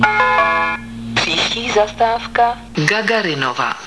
Hlášení zastávek ústecké MHD
Většina nahrávek byla pořízena ve voze ev.č. 516.
Na této stránce jsou kvalitnější zvuky, původní web obsahuje nahrávky horší zvukové kvality.